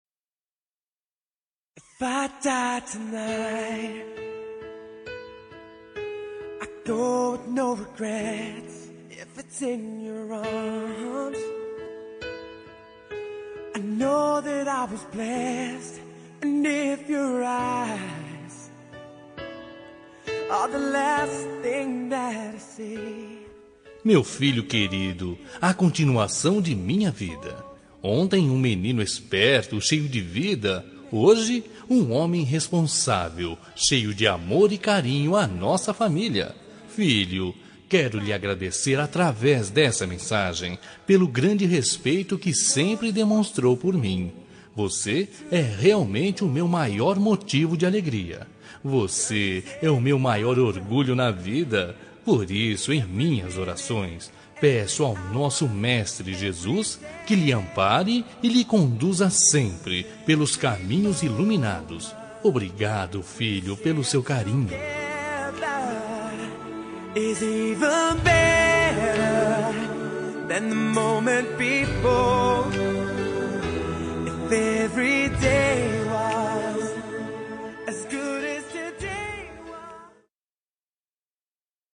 Telemensagem de Agradecimento – Para Filho – Voz Masculina – Cód: 30
agradecimento ao filho- masc-2026.mp3